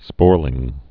(spôrlĭng)